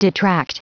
Prononciation du mot detract en anglais (fichier audio)
Prononciation du mot : detract